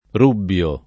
rubbio [ r 2 bb L o ]